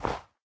sounds / step / snow3.ogg
snow3.ogg